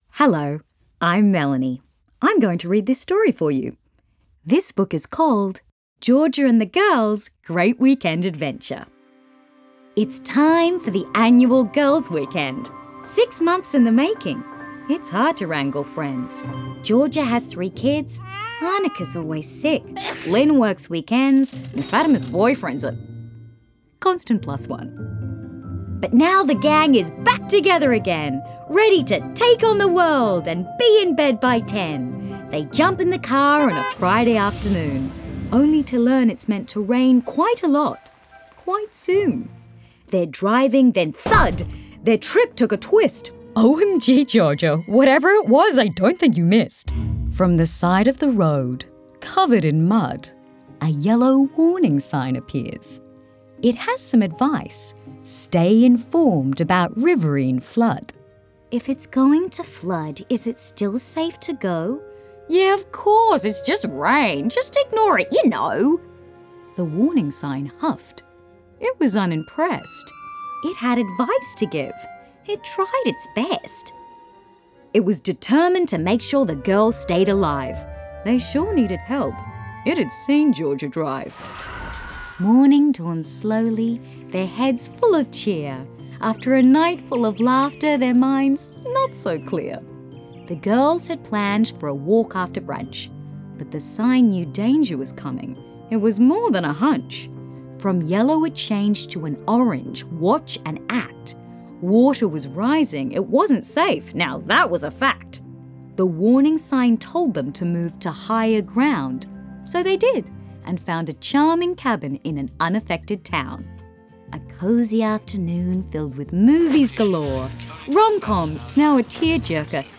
Each story featured on this landing page is available with PDF with an accompanying audiobook that can be used together, or separately.
AWS-Girls-Weekend_AudioBook.wav